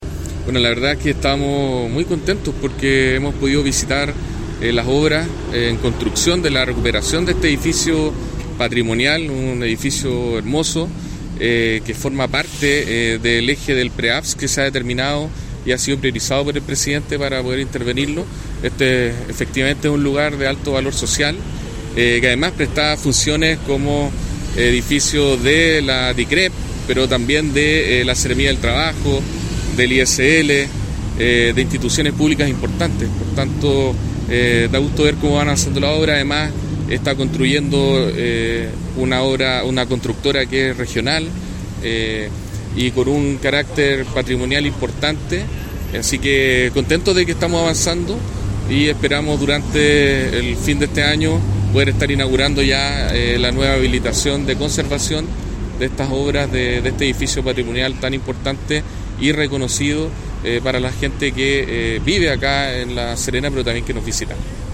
Tras recorrer las dependencias, el Delegado Presidencial Regional, Galo Luna Penna, constató que las faenas llevan un 15% de avance y destacó que
TIA-RICA-PREAVS-Delegado-Presidencial-Galo-Luna-Penna.mp3